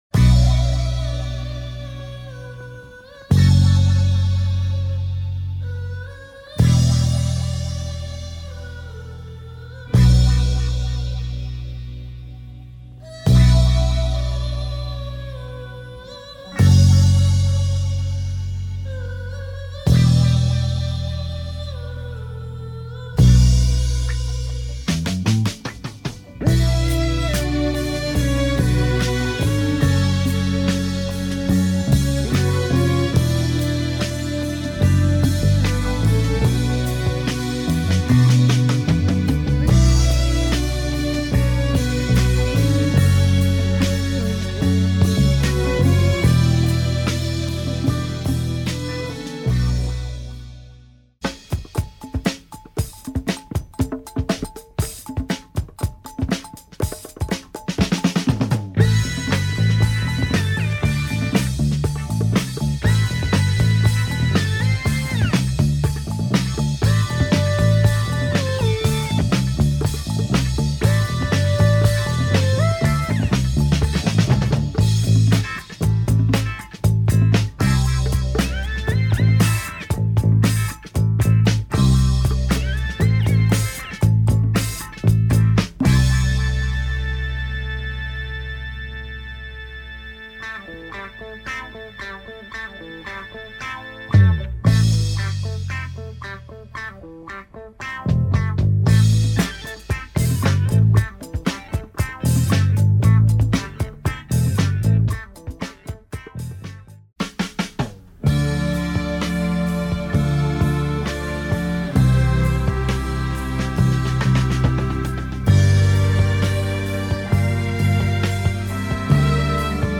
Fat drumbreak intro
Funky rock and breaks
Very nice Polish beats